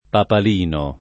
papalina [papal&na] s. f. («copricapo») — antiq. papalino [